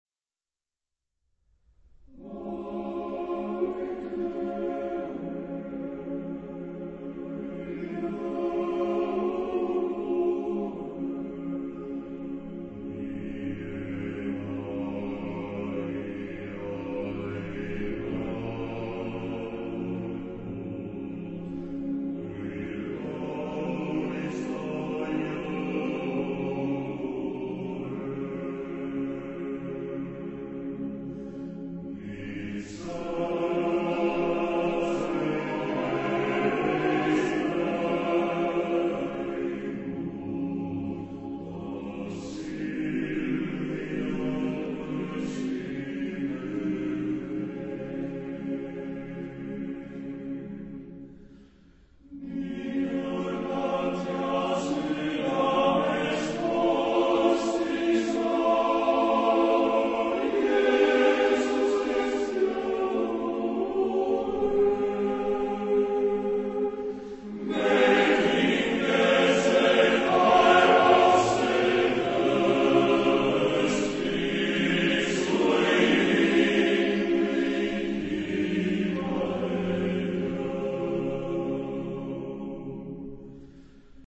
Genre-Style-Forme : Profane ; Chant de Noël
Caractère de la pièce : lent
Type de choeur : TTTBB  (5 voix égales d'hommes )
Tonalité : do majeur